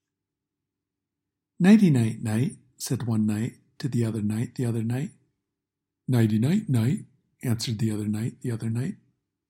Tongue Twister